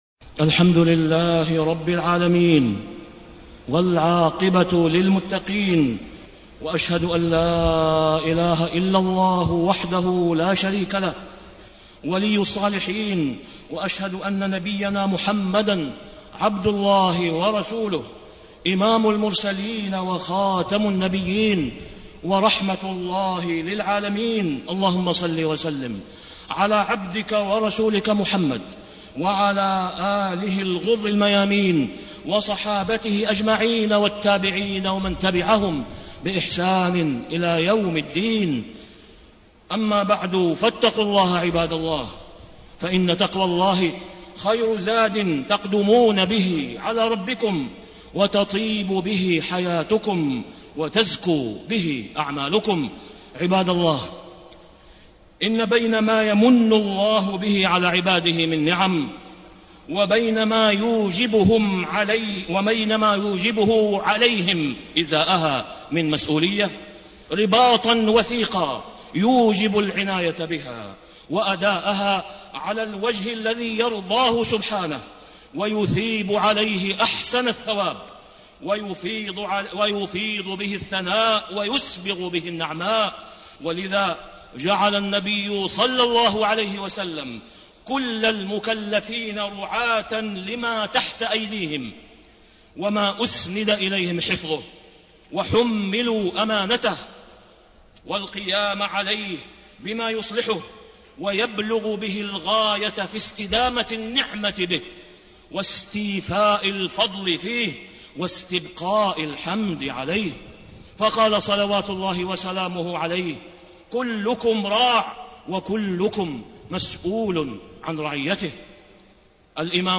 تاريخ النشر ١٠ ذو القعدة ١٤٣٥ هـ المكان: المسجد الحرام الشيخ: فضيلة الشيخ د. أسامة بن عبدالله خياط فضيلة الشيخ د. أسامة بن عبدالله خياط المسؤولية تجاه الأبناء في ضوء الكتاب والسنة